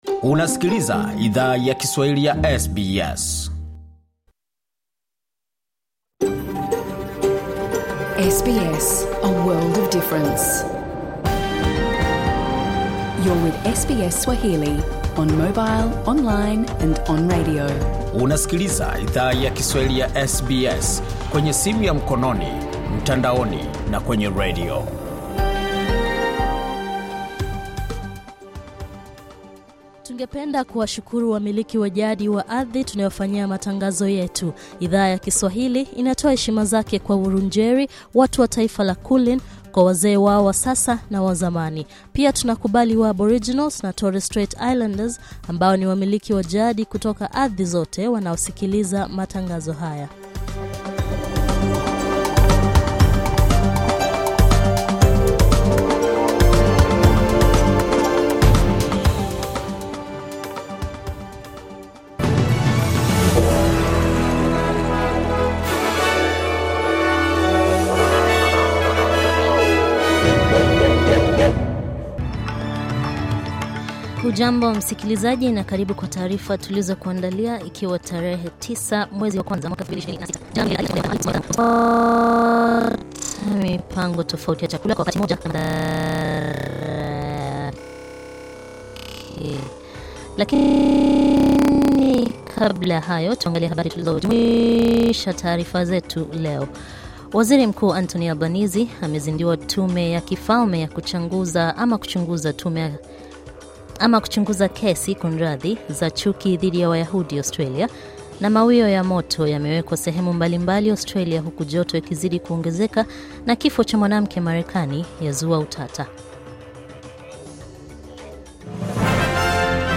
Taarifa ya habari:Tume ya kifalme yazinduliwa kuchunguza chuki dhidi ya wayahudi